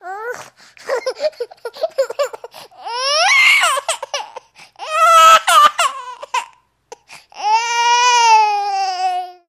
Baby: Crying And Screaming.